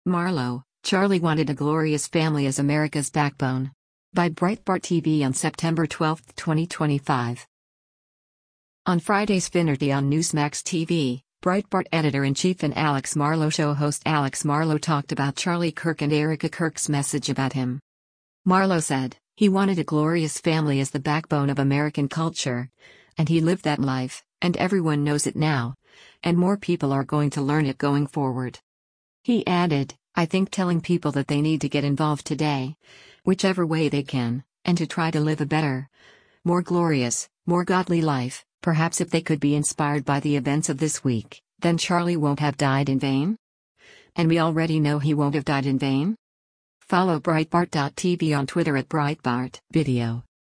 On Friday’s “Finnerty” on Newsmax TV, Breitbart Editor-in-Chief and “Alex Marlow Show” host Alex Marlow talked about Charlie Kirk and Erika Kirk’s message about him.